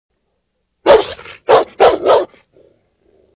دانلود آهنگ سگ هار از افکت صوتی انسان و موجودات زنده
دانلود صدای سگ هار از ساعد نیوز با لینک مستقیم و کیفیت بالا
جلوه های صوتی